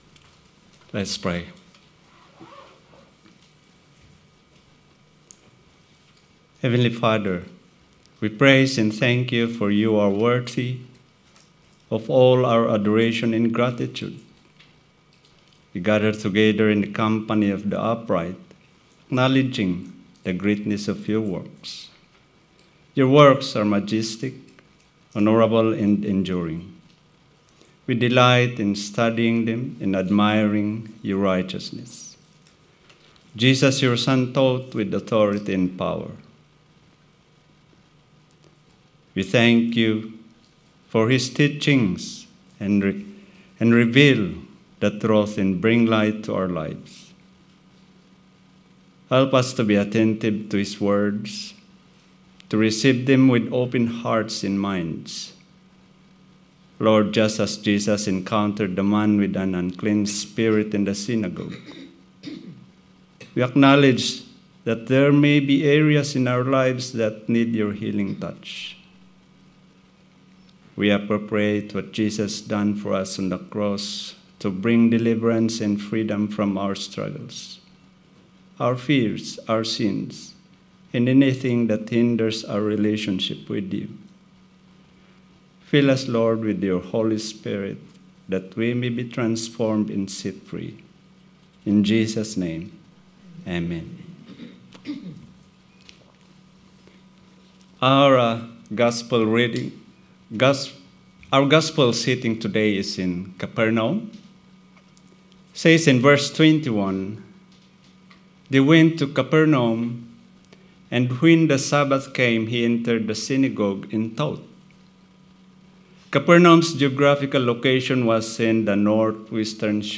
Sermon 28th January